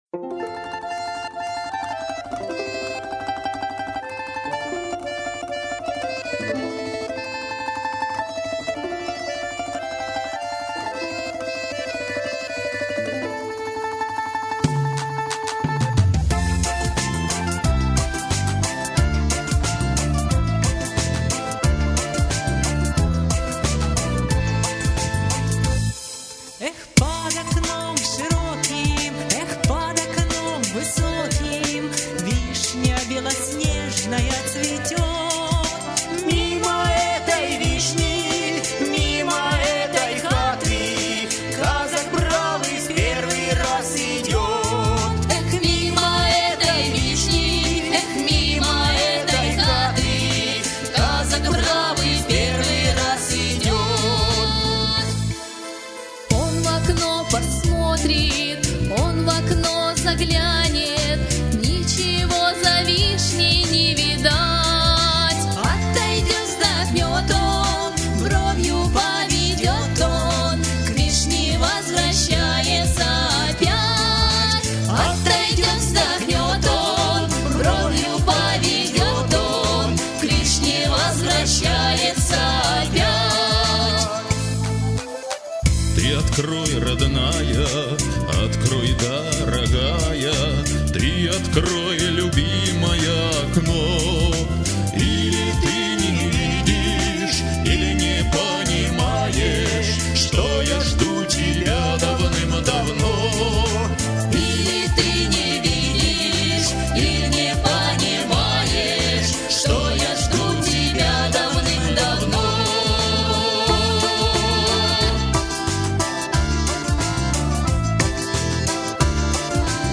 Лирическая народная песня.